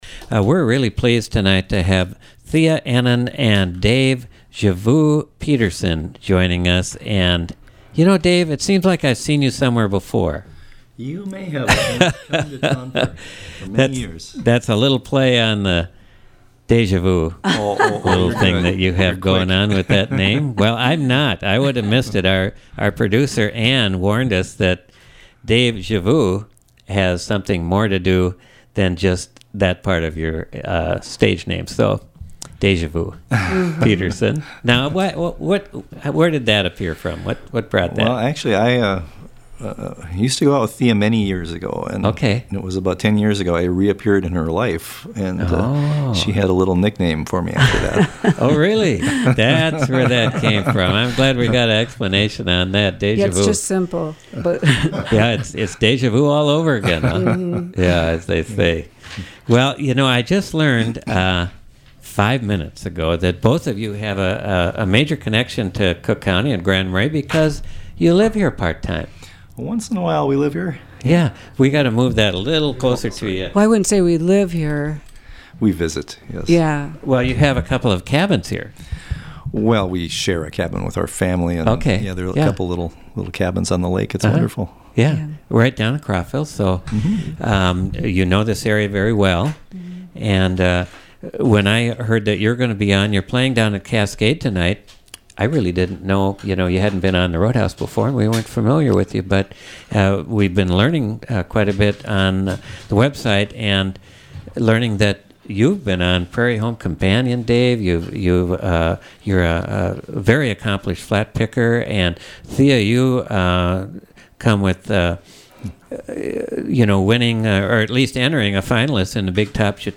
bluegrass and jazz guitarist